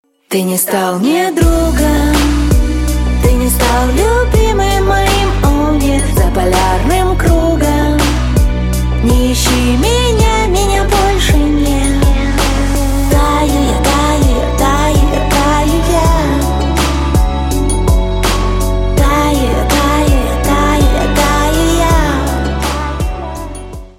• Качество: 320, Stereo
поп
женский вокал
грустные
спокойные